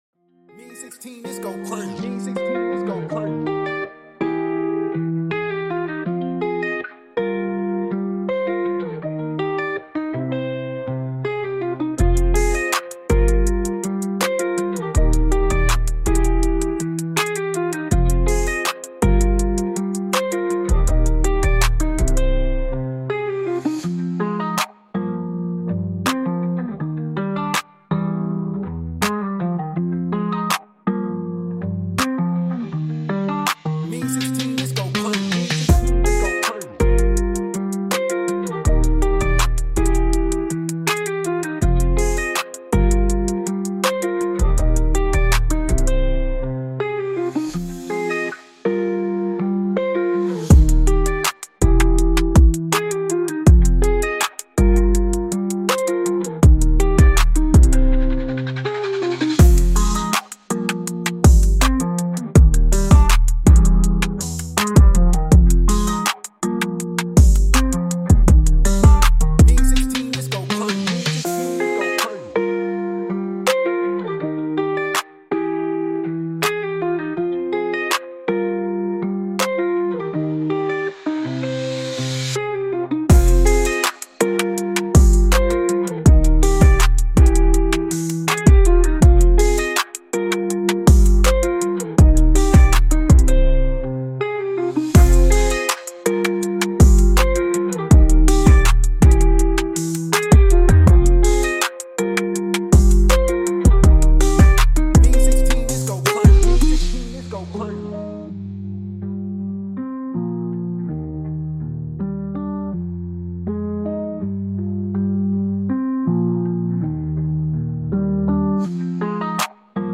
URBAN POP TYPE BEAT
F-Min 162-BPM